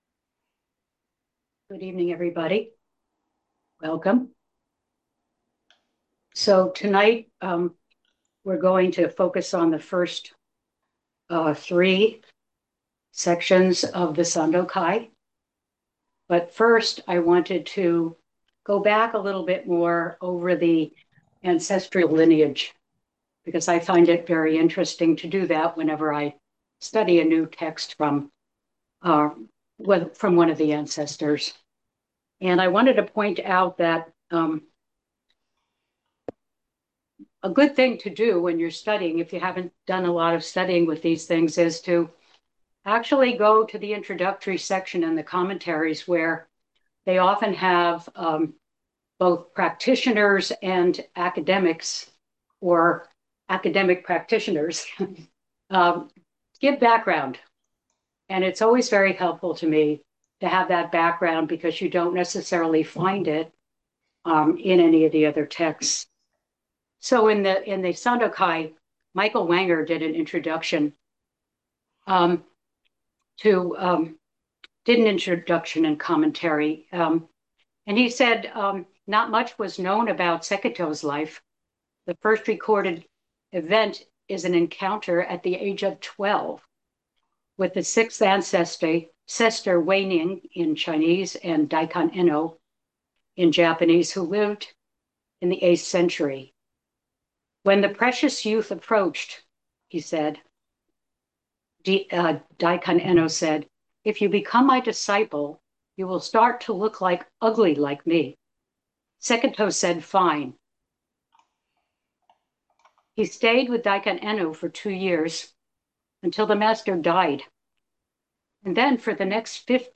Lectures, talks, and classes on Zen Buddhism from Shogakuji Temple, Berkeley Zen Center, in Berkeley, California, USA.